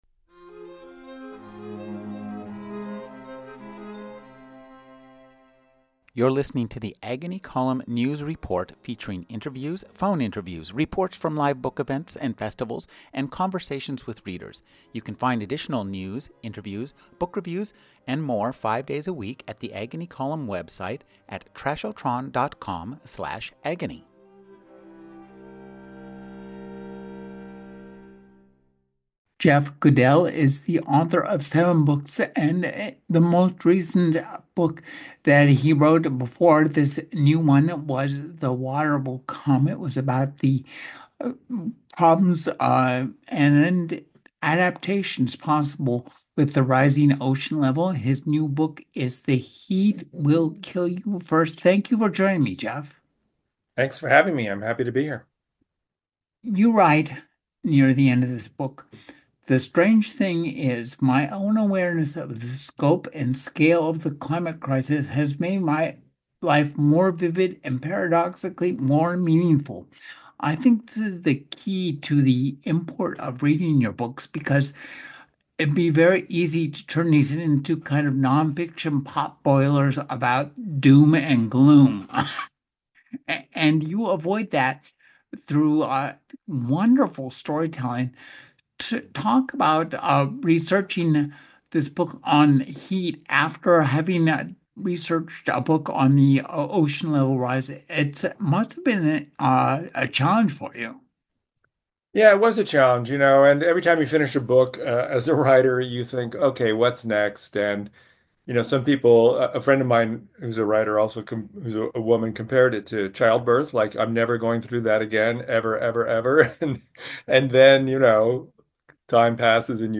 … continue reading 1 2255: A 2025 Interview with Jeff Goodell Play Pause 2M ago Play Pause التشغيل لاحقا التشغيل لاحقا قوائم إعجاب احب — Jeff Goodell discusses The Heat Will Kill You First: Life and Death on a Scorched Planet.